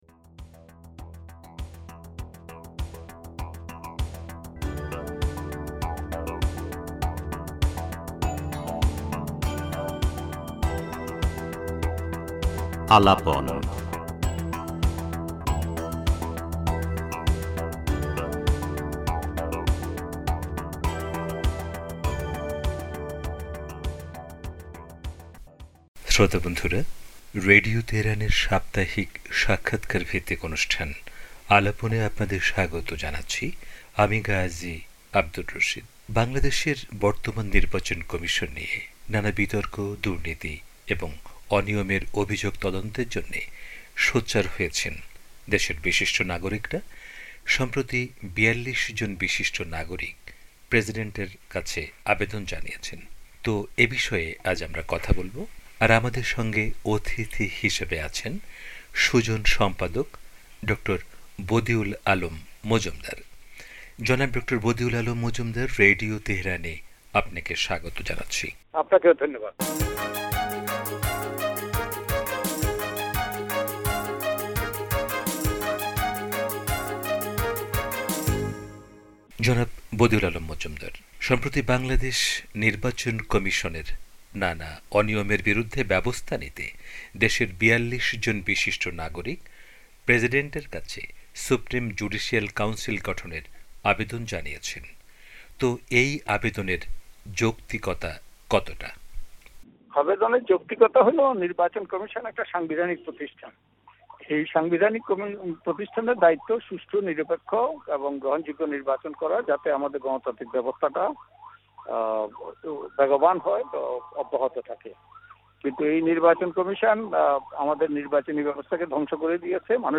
পুরো সাক্ষাৎকারটি তুলে ধরা হলো।